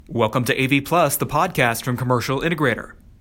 Below, you’ll find some audio samples recorded with three different microphones: the MXL Overstream bundle, a sub-$50 USB microphone made by a different manufacturer, and my iPhone 8’s voice recorder.
To keep these tests useful and consistent, I recorded them all in the same room and compressed the raw files into .MP3 format.
Here’s my iPhone 8:
The sub-$50 USB microphone sounded thin and brittle, and the audio using an iPhone wasn’t as rich or as detailed, either.
iPhone.mp3